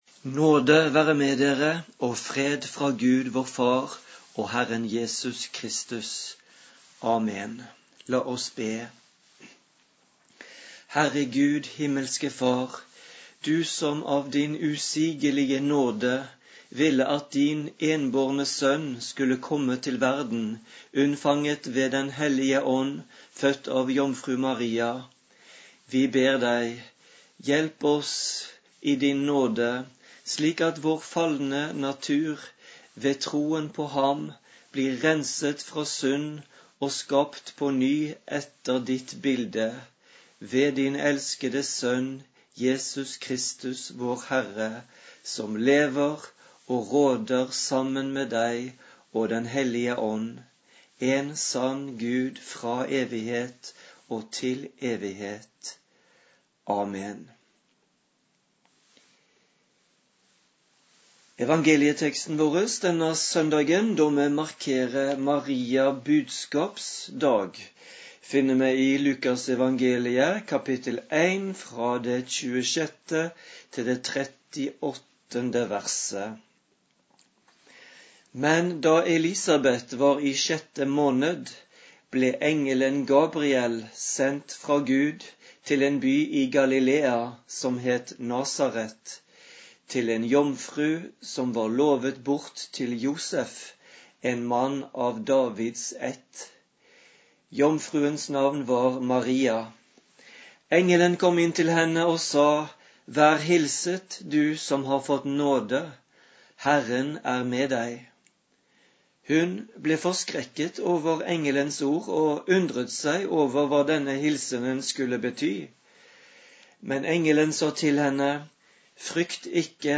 Maria-budskapsdag.mp3